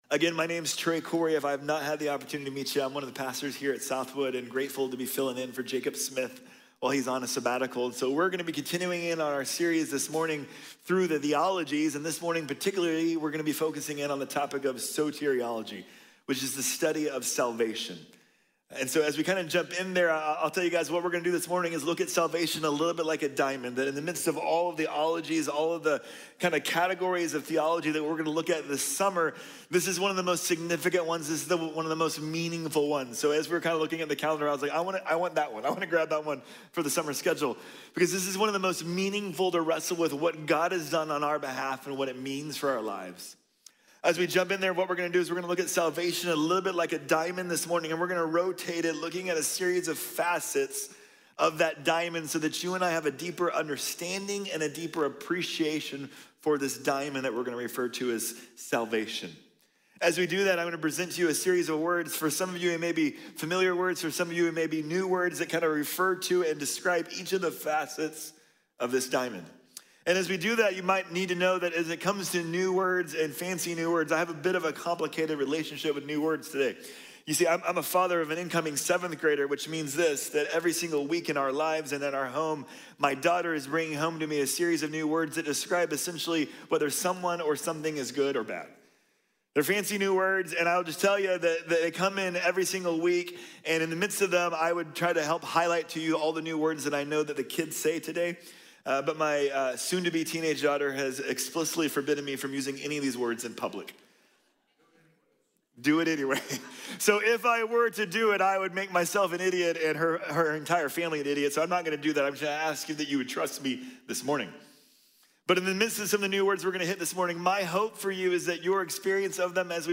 Soteriología | Sermón | Iglesia Bíblica de la Gracia